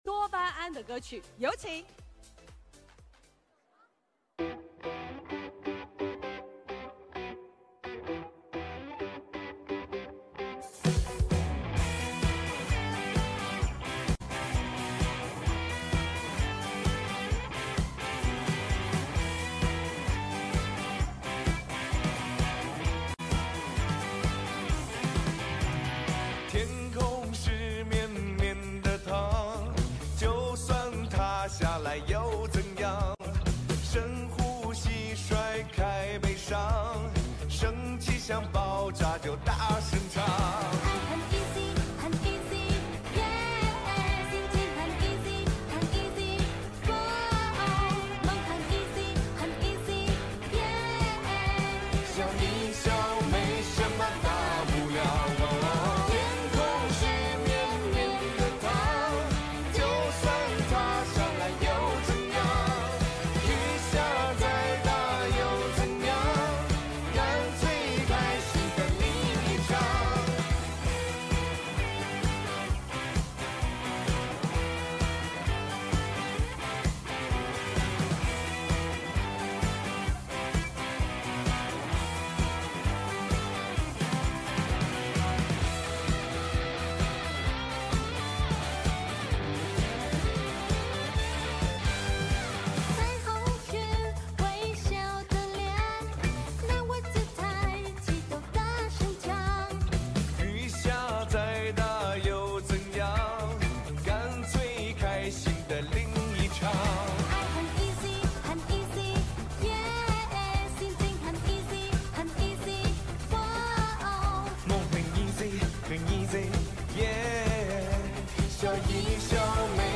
song ca